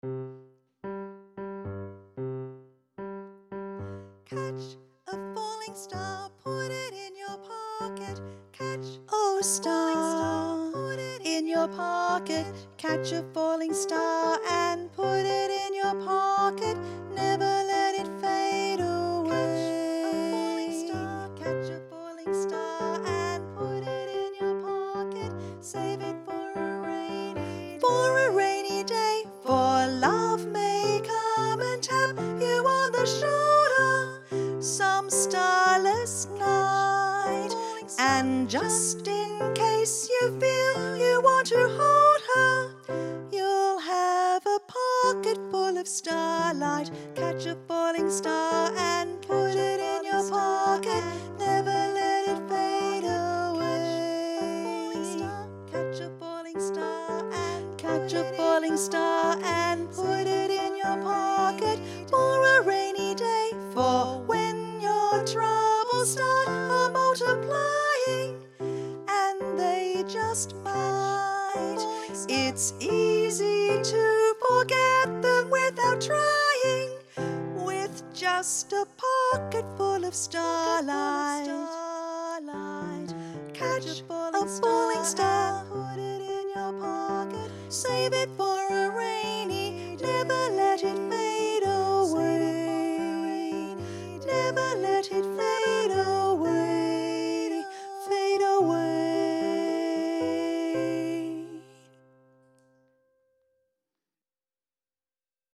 Vox Populi Choir is a community choir based in Carlton and open to all comers.